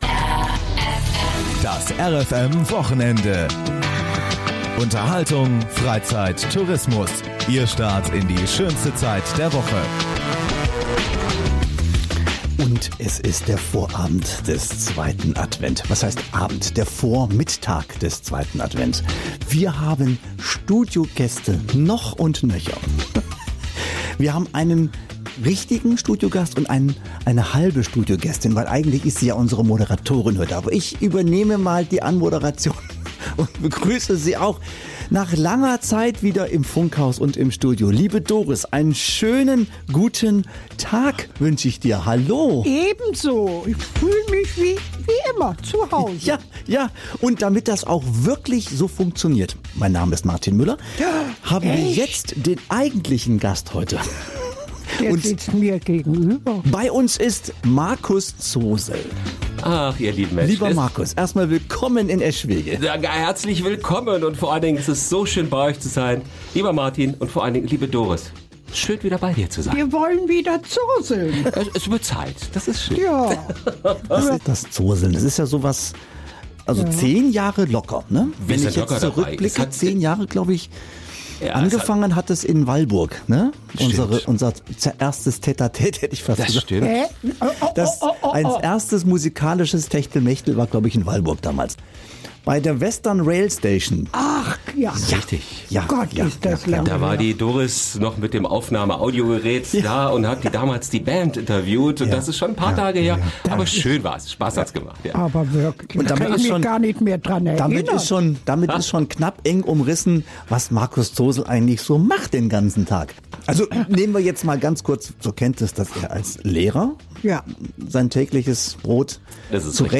Die Sendung wurde am 6. Dez. im Funkhaus in Eschwege aufgezeichnet und enthält wieder viel lustigen Talk und Weihnachs-Songs aus eigener und fremder Feder - Kult eben.